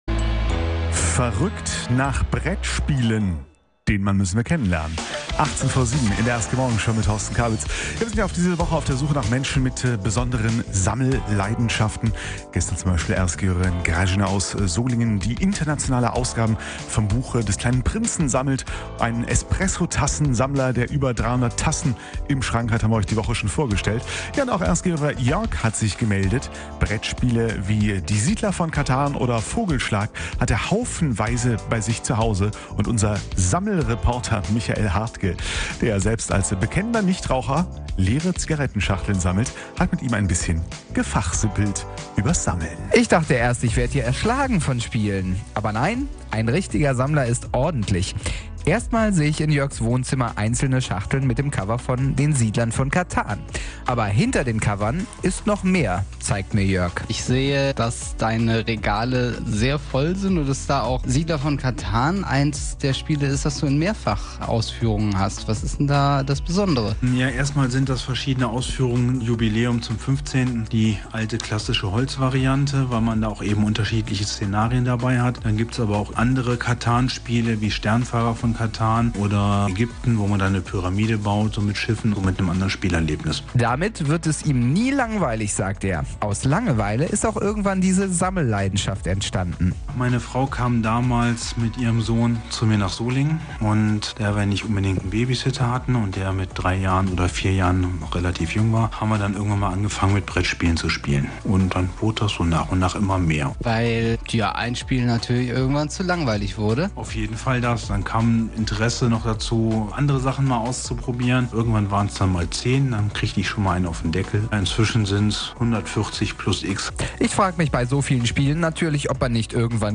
Ein paar Sammler aus Solingen und Remscheid hat unser Reporter besucht...